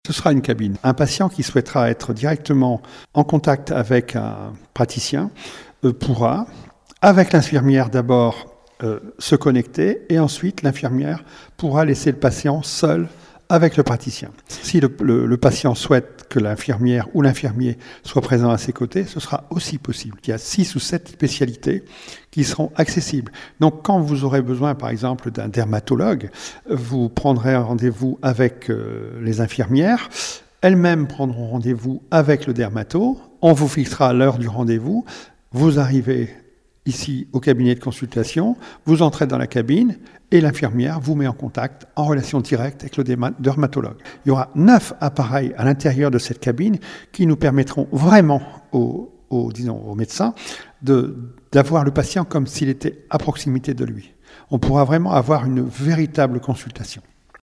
M. THOMAS – Maire de la commune de MOULLE